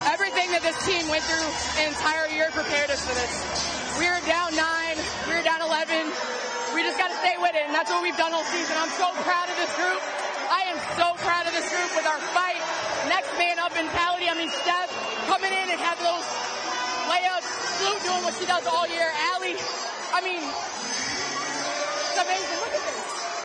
In a postgame interview, Parker highlighted what she loved about the team and how she is thankful to play a team sport where it is not just about herself.